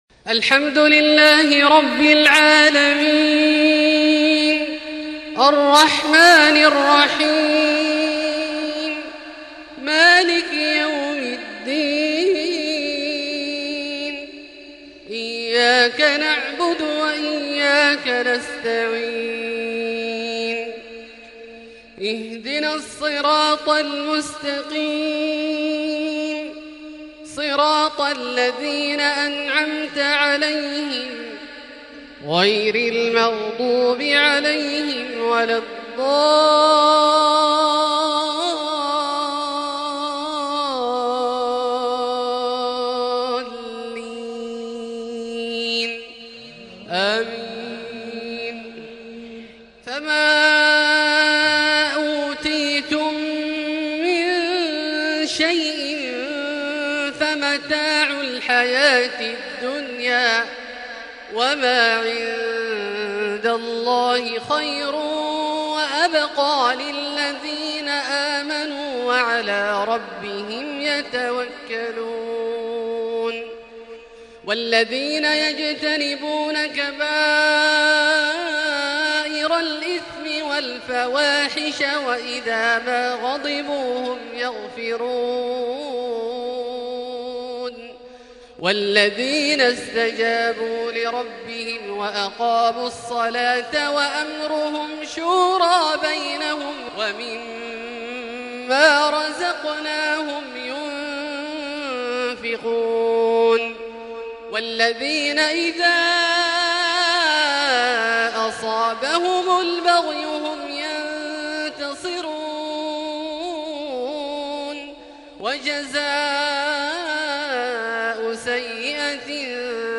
تحبير عذب من آخر سورة الشورى (36-53) عشاء ٩ / ٨ / ١٤٤١ > ١٤٤١ هـ > الفروض - تلاوات عبدالله الجهني